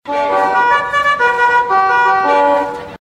CALL TO POST HORN.mp3
A military horn, calling troopers to arms.
call_to_post_horn_epz.ogg